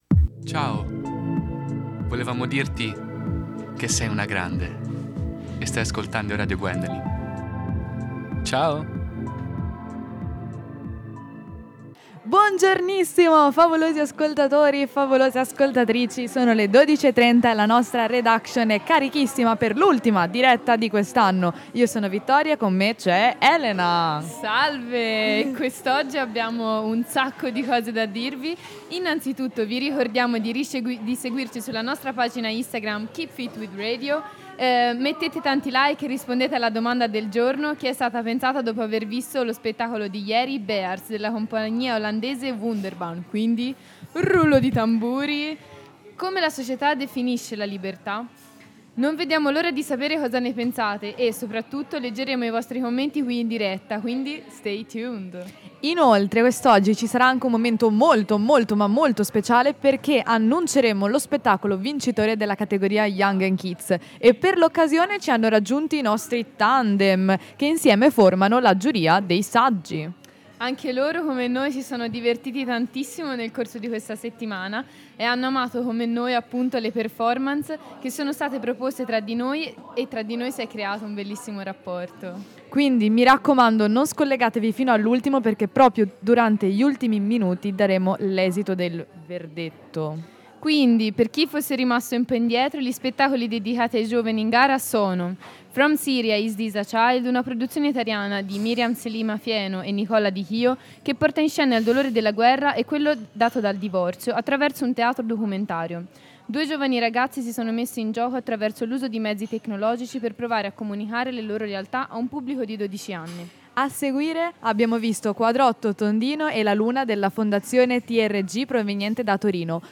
All postazione radiofonica al Bar Portici di Lugano: